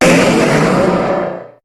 Cri de Méga-Kangourex dans Pokémon HOME.
Cri_0115_Méga_HOME.ogg